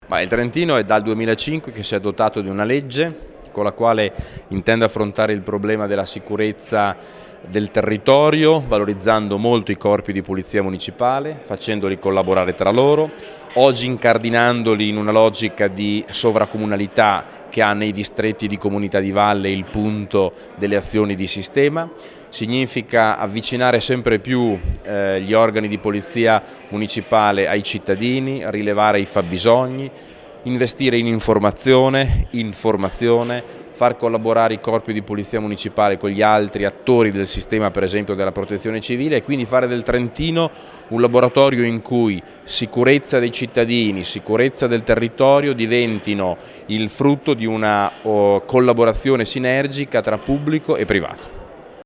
intervista all'assessore Olivi